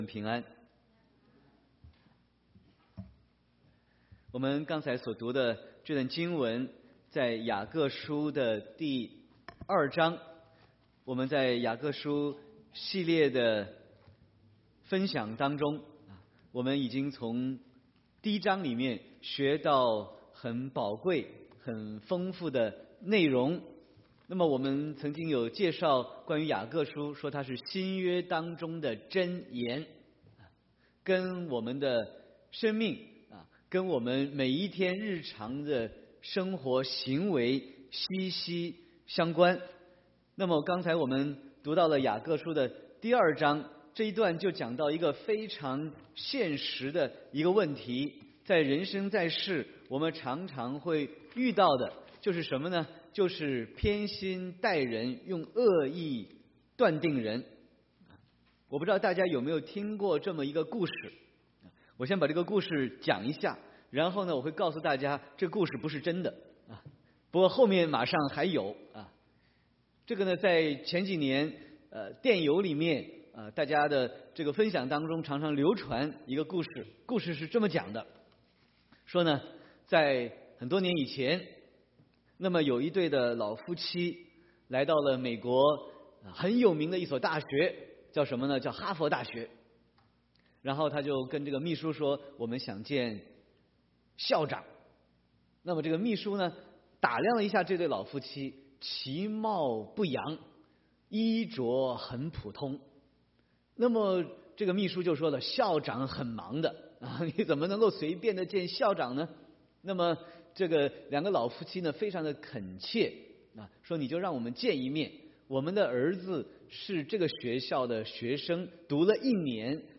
Sermon 4/8/2018